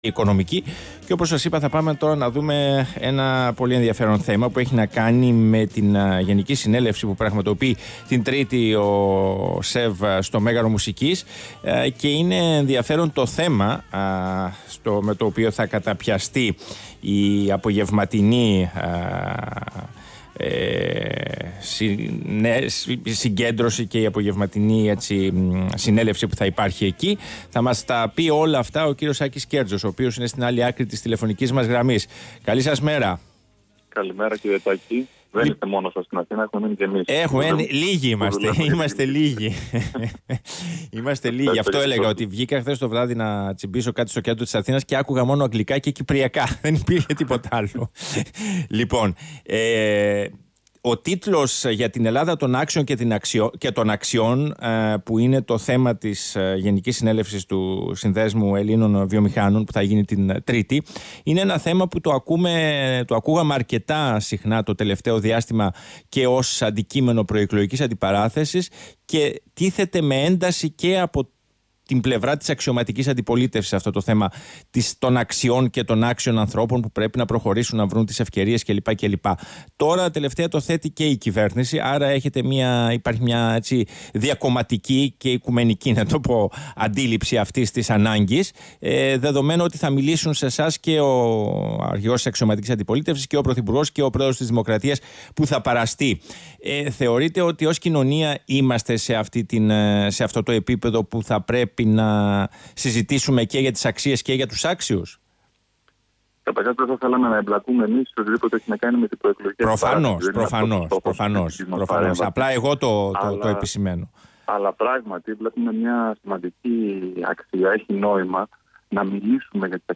Συνέντευξη του Γενικού Διευθυντή του ΣΕΒ, κ. Άκη Σκέρτσου στον Ρ/Σ ΠΑΡΑΠΟΛΙΤΙΚΑ 90,1, 17/6/2019